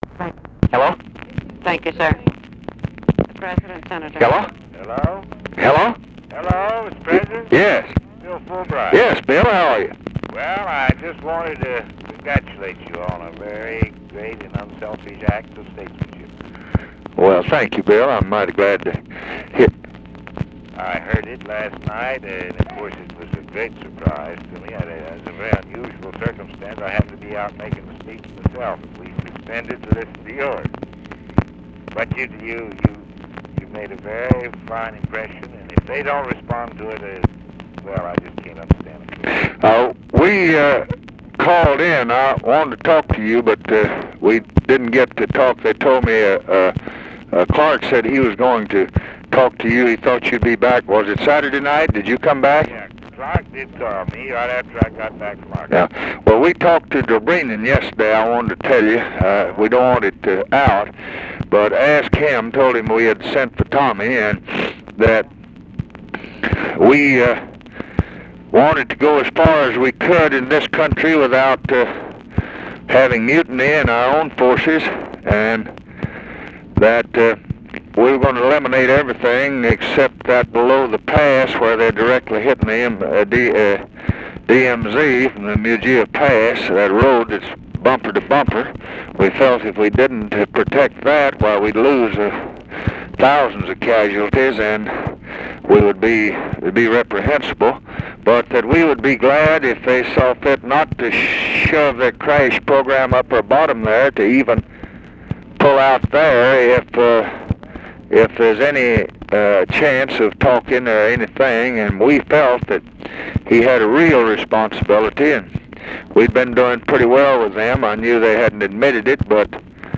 Conversation with WILLIAM FULBRIGHT, April 1, 1968
Secret White House Tapes